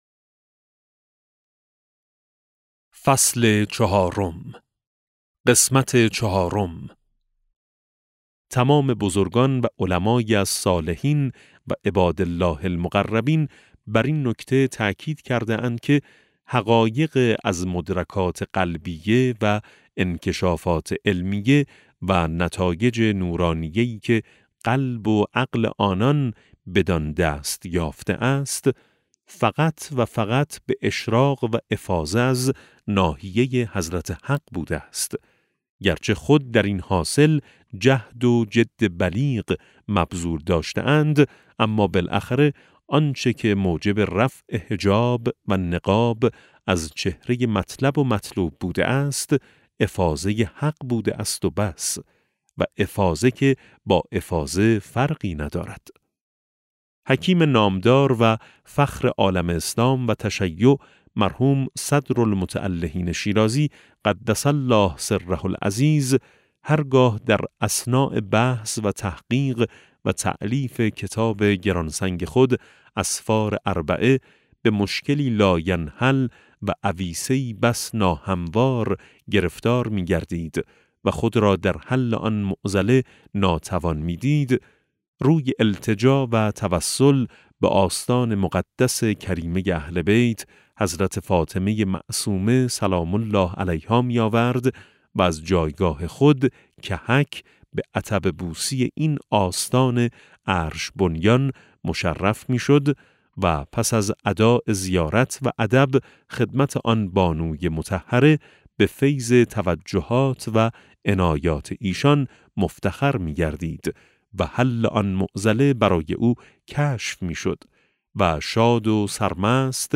افق وحی - فصل چهارم (337 ـ 351) کتاب صوتی افق وحی - جلسه 19 پدیدآور آیت‌اللَه سید محمدمحسن حسینی طهرانی توضیحات افق وحی - فصل چهارم: بررسی مطالب مطرح شده از طرفین - صفحه (337 ـ 351) متن این صوت دانلود این صوت